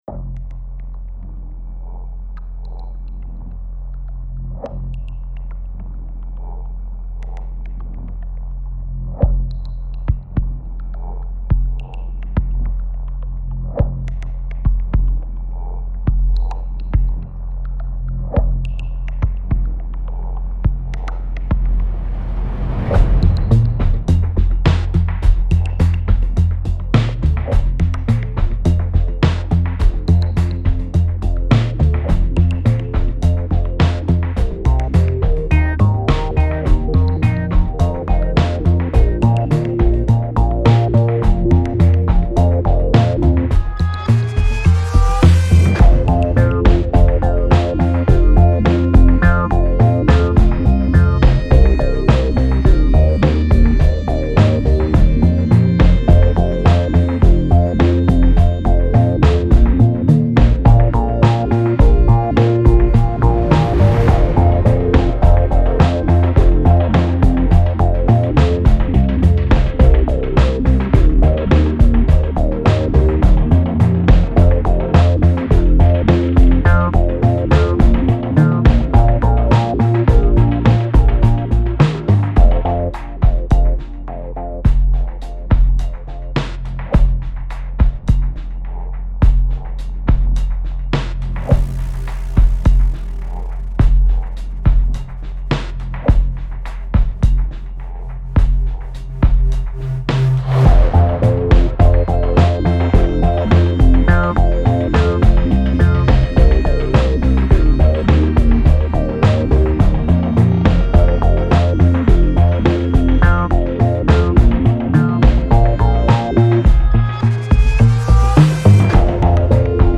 cool
dissonant
geheimnisvoll
Drums
repetitiv
Electro
elektronisch
nervös